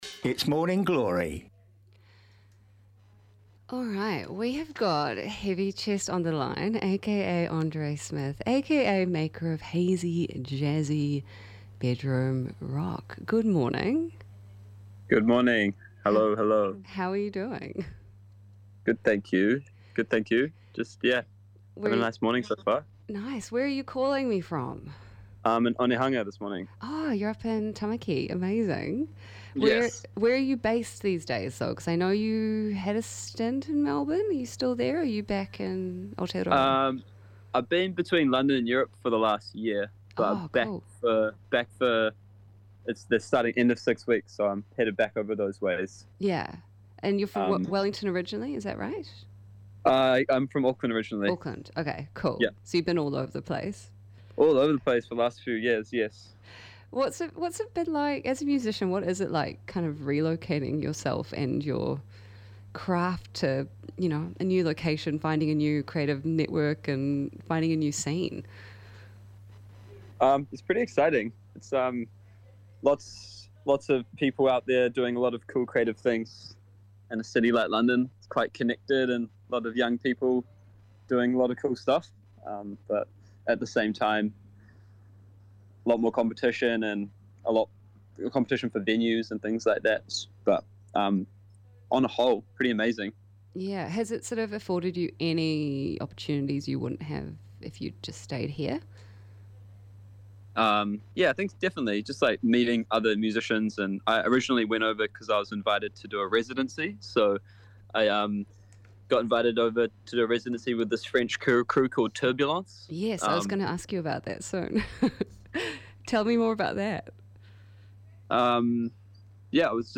95bFM's daily magazine show, a signature mix of arts, culture, interviews & features, and great music.